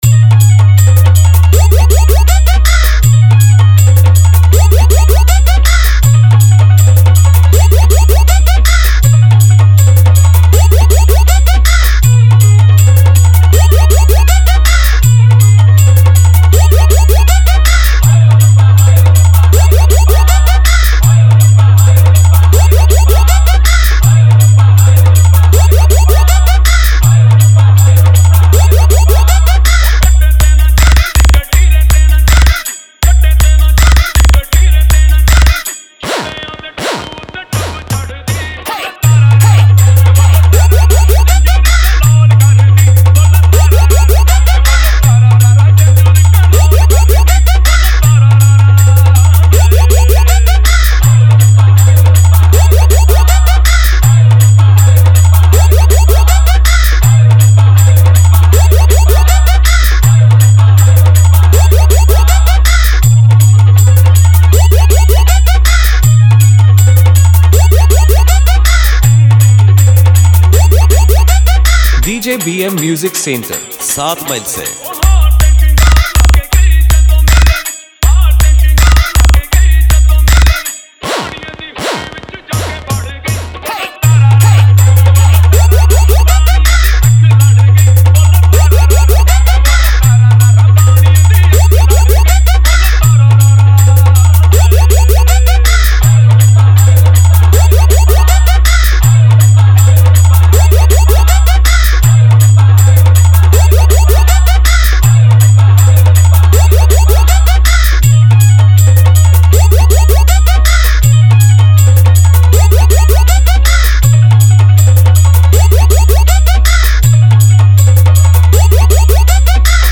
New Style Competition 1 Step Long Humming Pop Bass Mix 2025